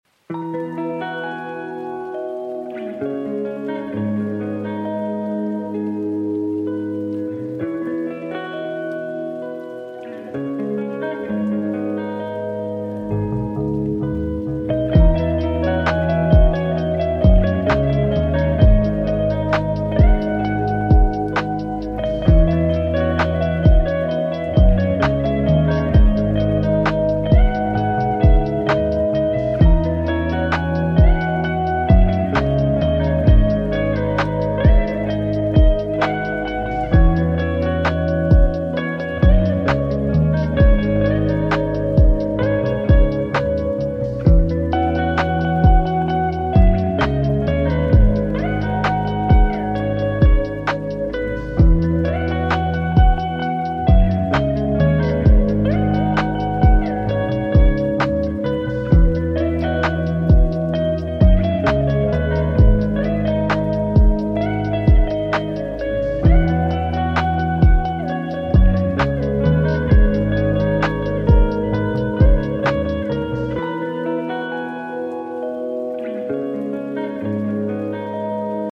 Lofi/Chill Guitar